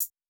Hat  (7).wav